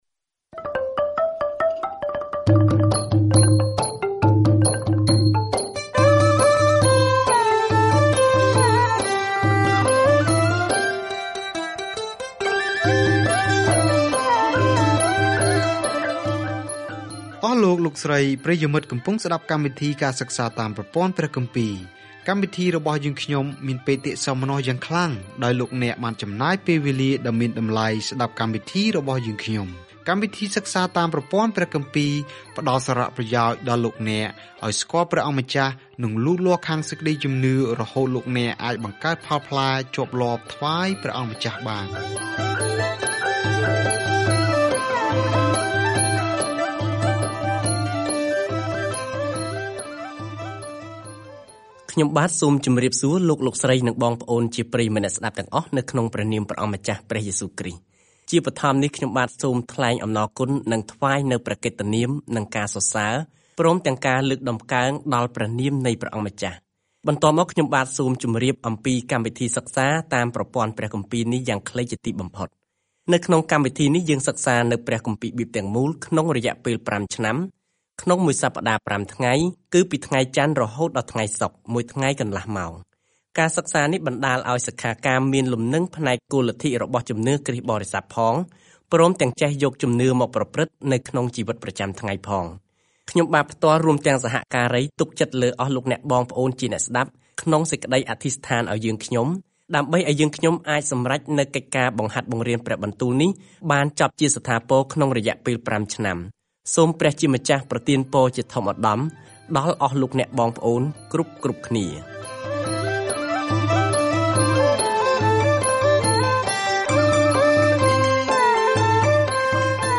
បុរសសាមញ្ញម្នាក់ឈ្មោះនេហេមា សង់ជញ្ជាំងជុំវិញទីក្រុងឡើងវិញនៅក្នុងសៀវភៅប្រវត្តិសាស្ត្រចុងក្រោយនេះ។ ការធ្វើដំណើរជារៀងរាល់ថ្ងៃតាមរយៈនេហេមា ពេលអ្នកស្តាប់ការសិក្សាជាសំឡេង ហើយអានខគម្ពីរដែលជ្រើសរើសពីព្រះបន្ទូលរបស់ព្រះ។